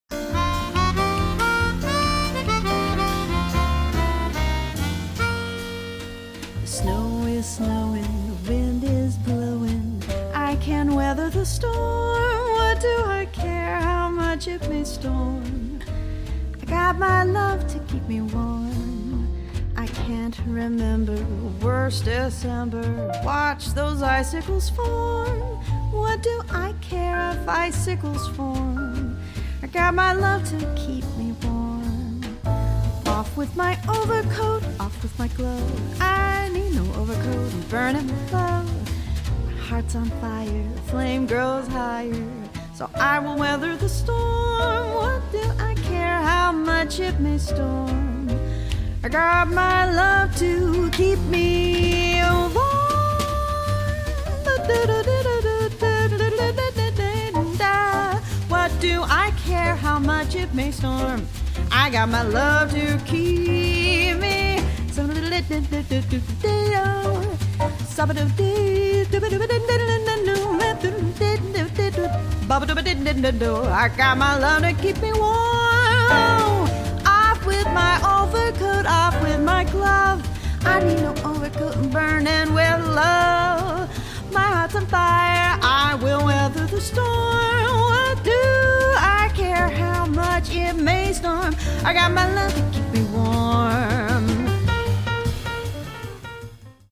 Singer and bassist
a warm, elegant and engaging holiday season recordings
harmonica
piano
drums